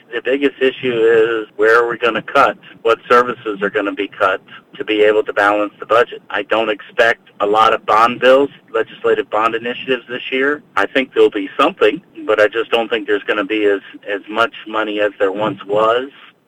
During the 90 day session, state lawmakers and Governor Wes Moore have financial challenges centered around a looming deficit. Senator Mike McKay tells WCBC News that the key is finding a way to balance the budget…